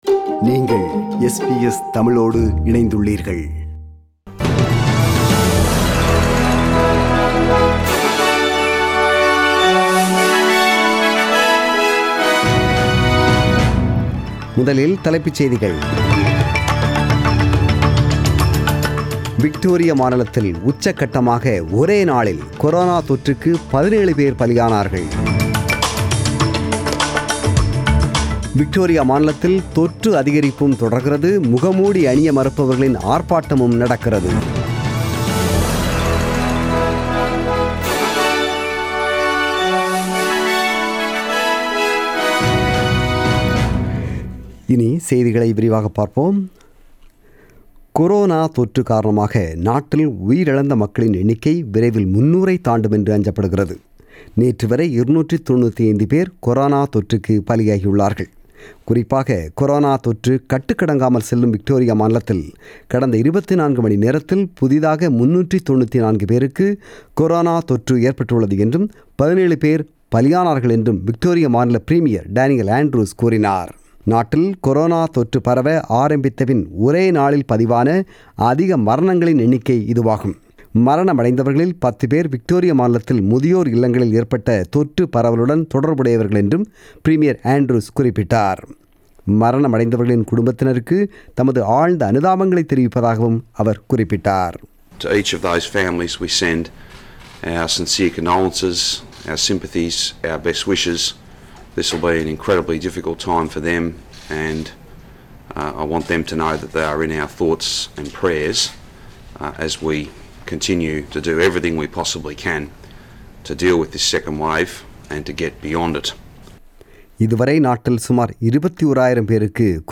The news bulletin was broadcasted on 9 August 2020 (Sunday) at 8pm.